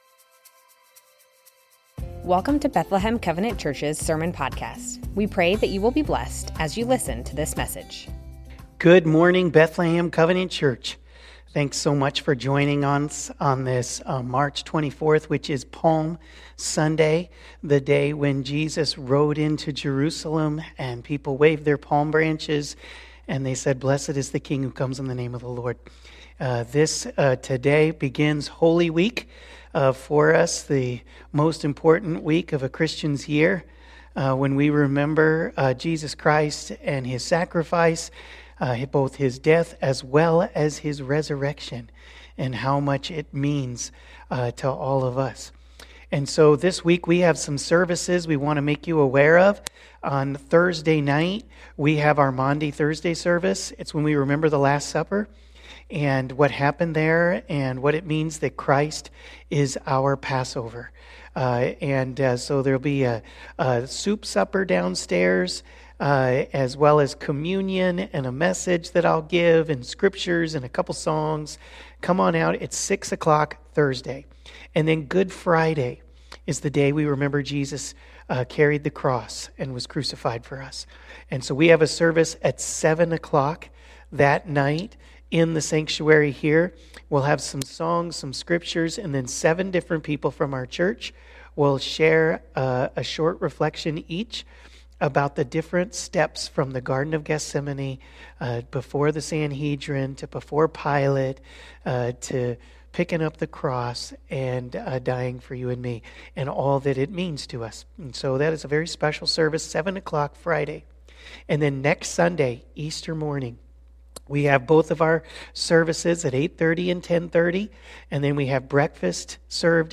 Bethlehem Covenant Church Sermons Jesus, Our King - Gentle, riding on a donkey Mar 24 2024 | 00:33:27 1x 00:00 / 00:33:27 Subscribe Share Spotify RSS Feed Share Link Embed